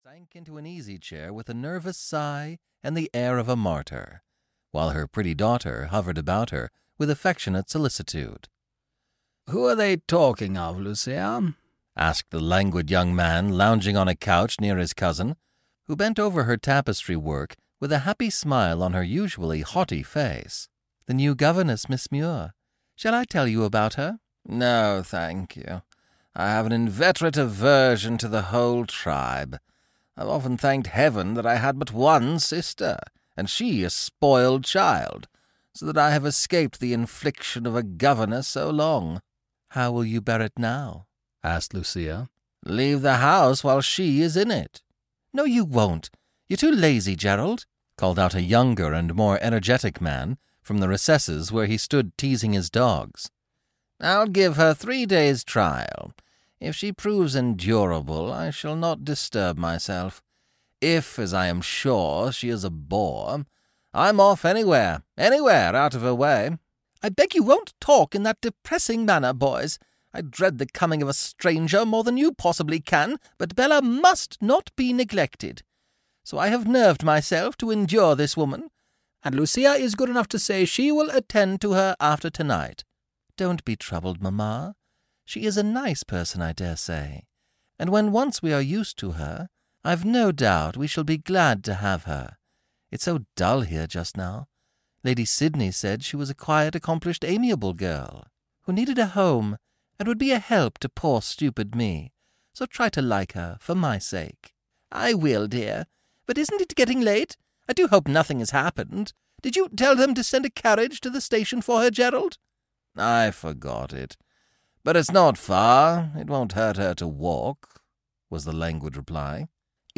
Ukázka z knihy
He masterfully plays with a wide array of voices and accents and has since then produced over 500 audiobooks.